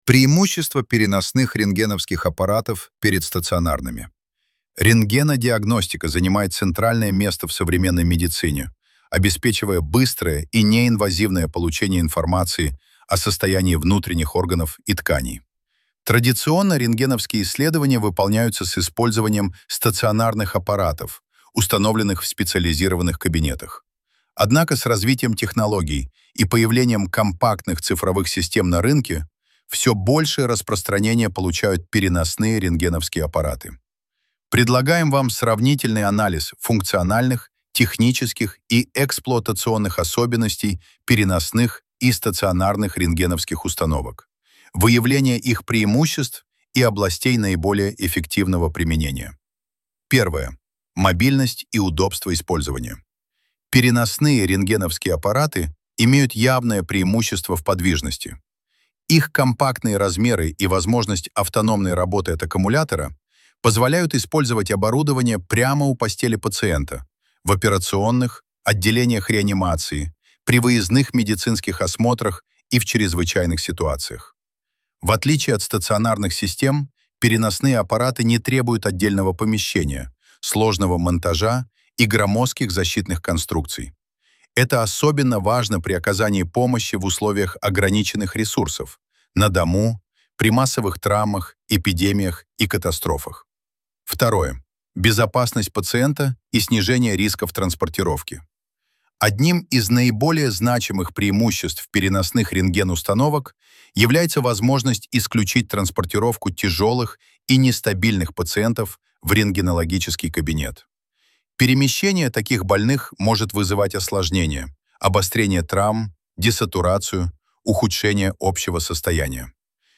ДОКЛАД
для Конгресс Российского общества рентгенологов и радиологов